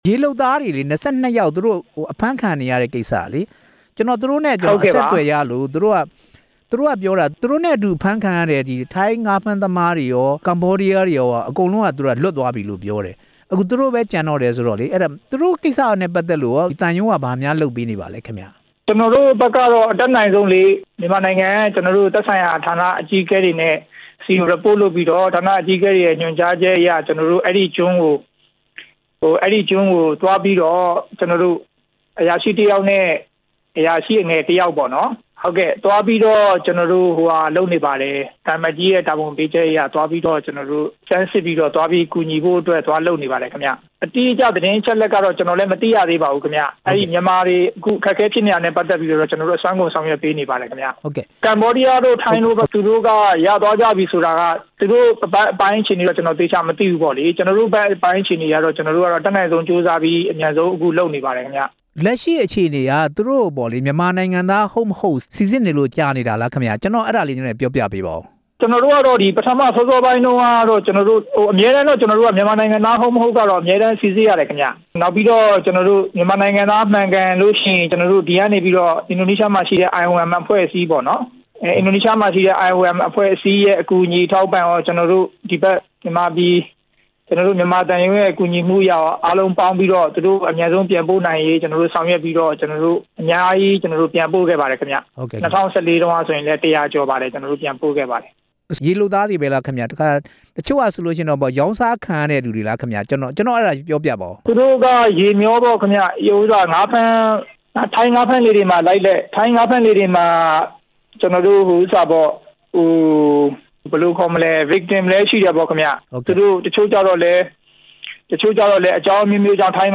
အင်ဒိုနီးရှားက မြန်မာရေလုပ်သား ၂၂ ဦး အခြေအနေ မေးမြန်းချက်